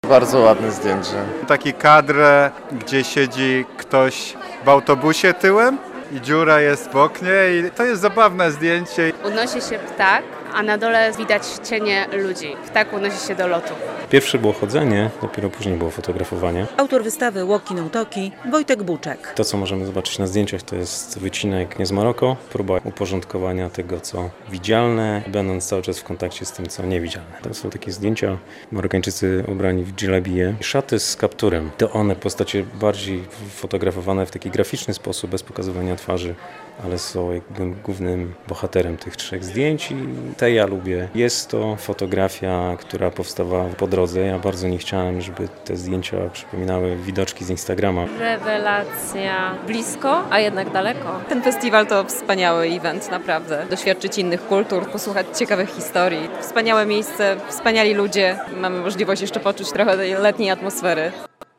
dźwięk do pobraniaW Białymstoku trwa Festiwal Kultur i Podróży "Ciekawi Świata" - relacja